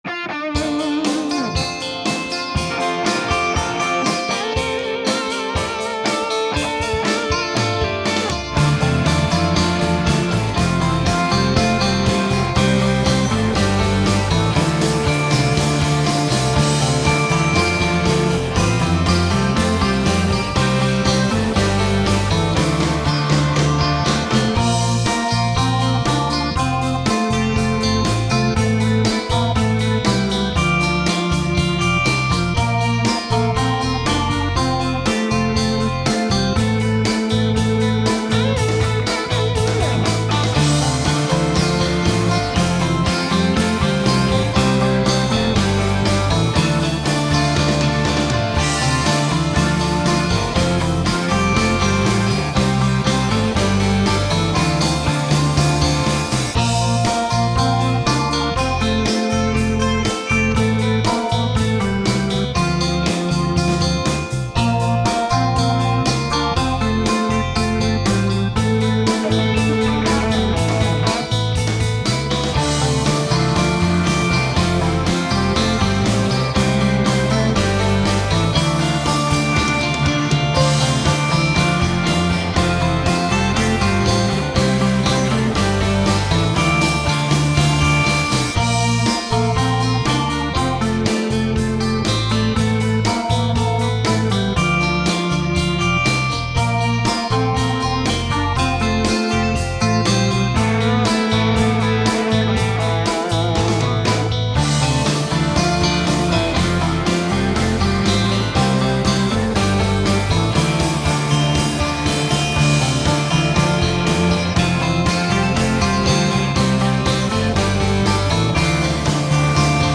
My backing is a little heavier.